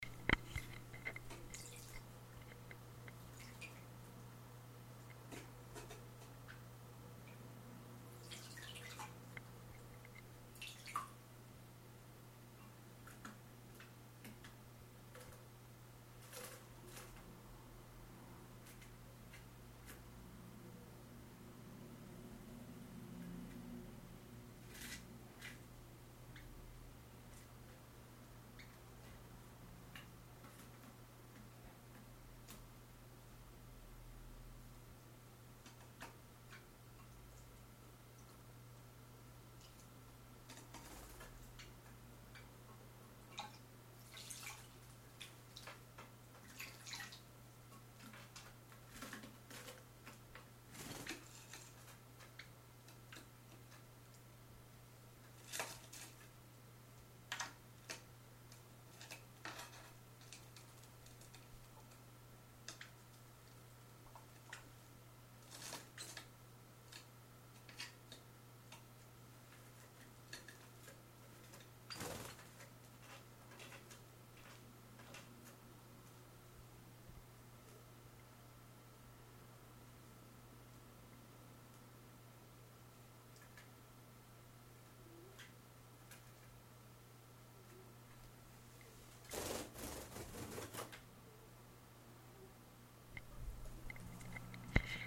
Canaries bathing
Mathilde and Darius having funwhile bathing in the guinea pigs' bowl